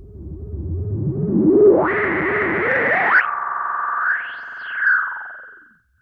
Filtered Feedback 15.wav